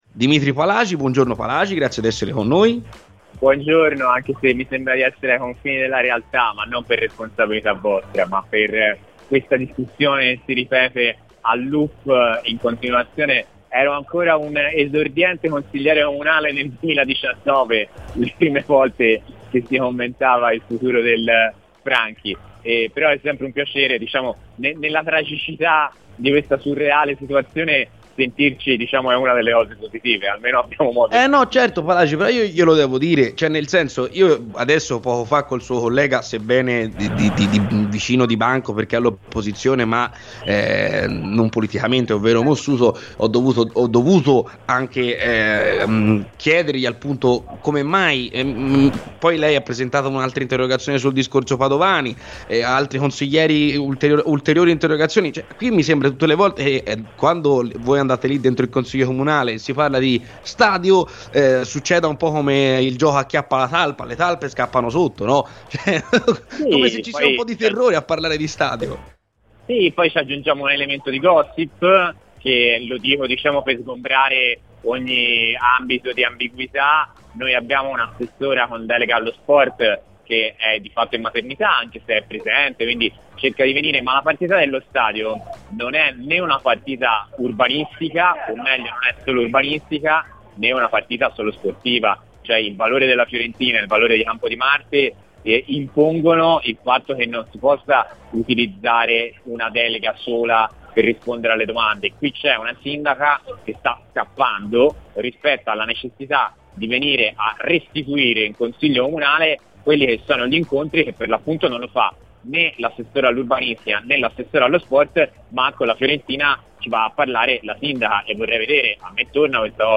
Il Consigliere Comunale di Firenze e membro di Sinistra Progetto Comune, Dimitri Palagi è intervenuto ai microfoni di Radio FirenzeViola durante la trasmissione "C'è polemica" raccontando a proposito della questione Franchi: "Mi sembra di essere ai confini della realtà… Ero ancora un esordiente consigliere comunale e si commentava il futuro del Franchi, e qui siamo ancora.